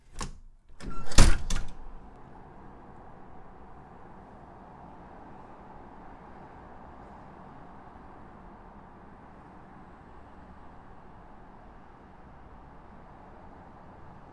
ホテルの窓開ける
op_wndw_safe_lock.mp3